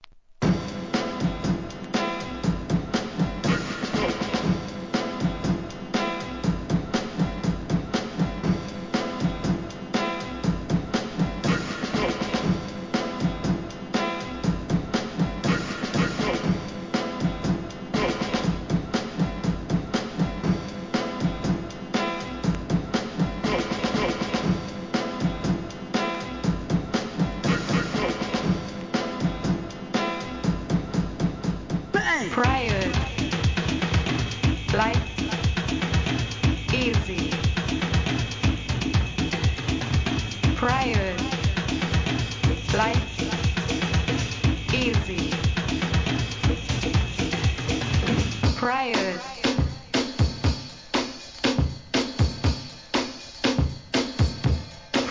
A-1に周期的なノイズ箇所有り、SAMPLEご確認ください